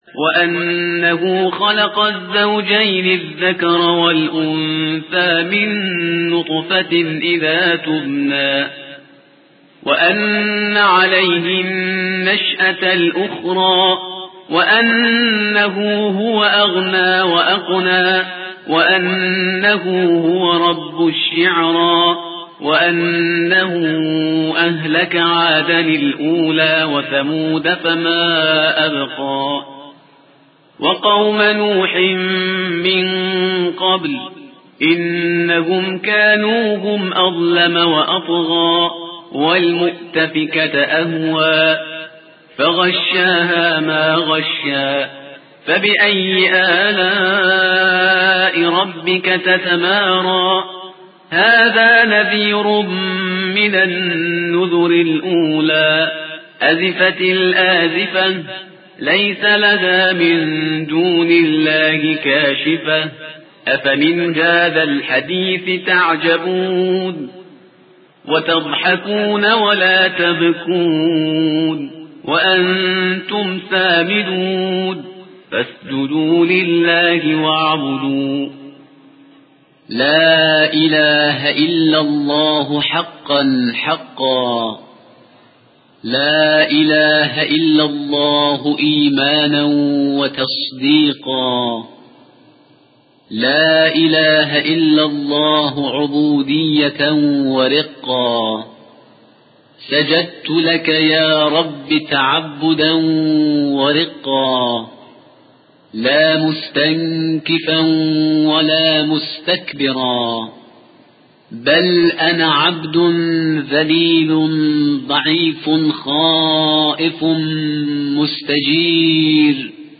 ترتیل